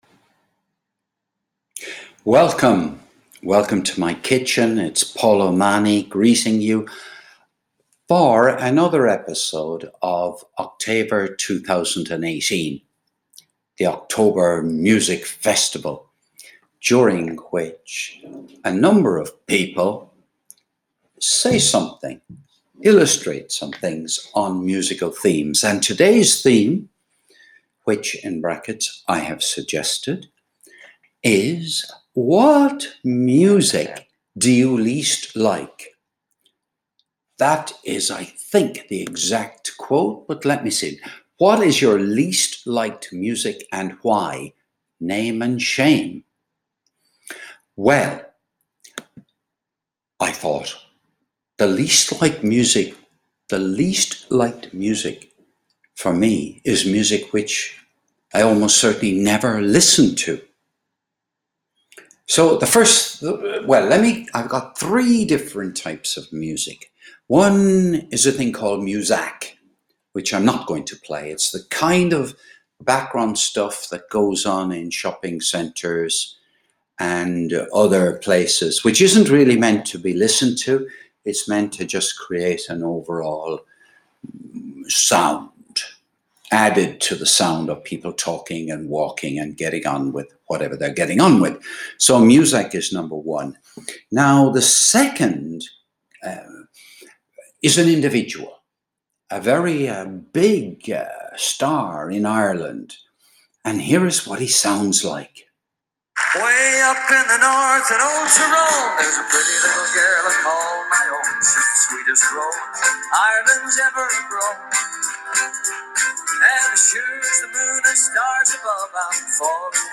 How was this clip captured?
This was recorded while showering on the morning of Saturday 22nd of February 2025